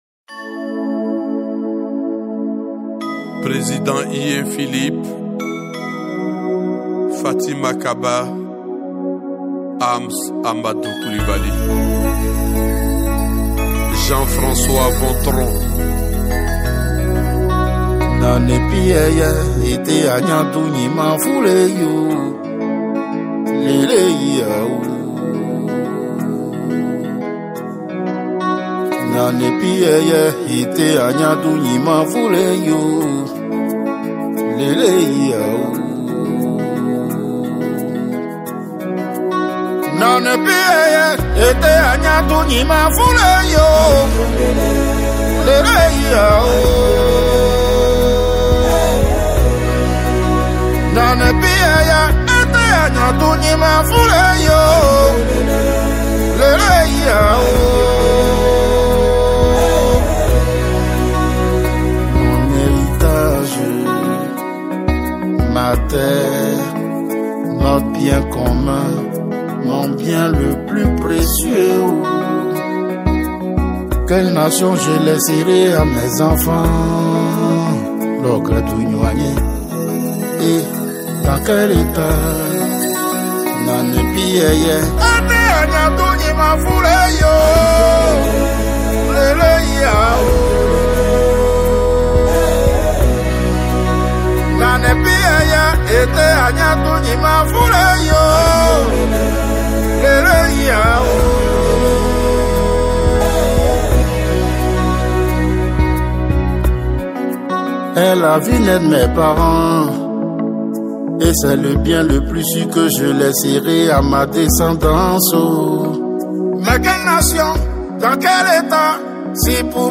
Type de culte : Culte Ordinaire
Type message : Exhortation